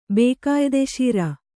♪ bē kāyde śira